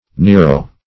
Nero \Ne"ro\ (n[=e]"r[-o]), prop. n.